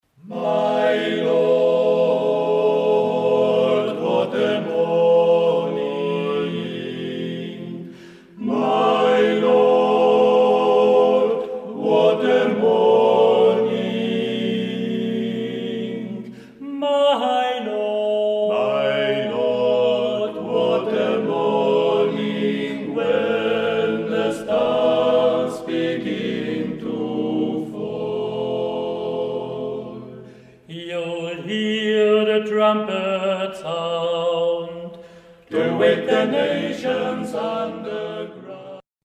• Aufgenommen im März 2005 in der Volksschule Poggersdorf
Spiritual) Kleingruppe